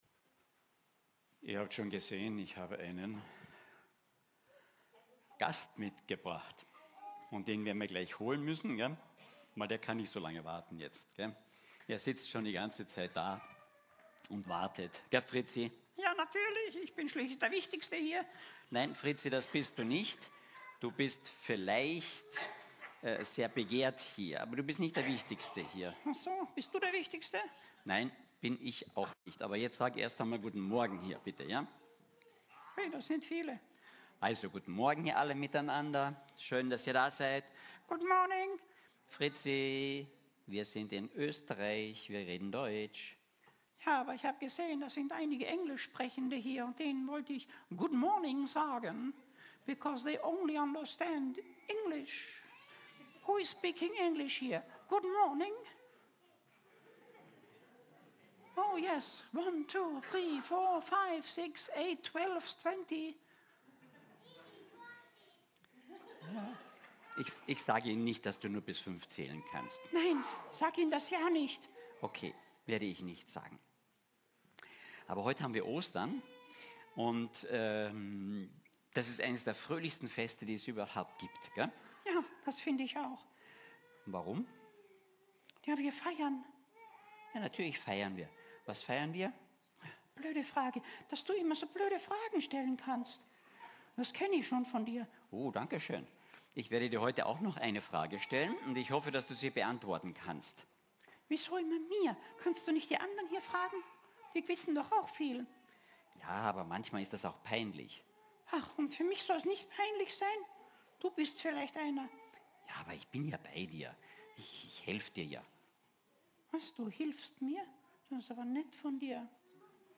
Predigtserie “Gott ist… wir sind…” – FEG Klagenfurt
Du-darfst-kommen-Osterpredigt_mp3.mp3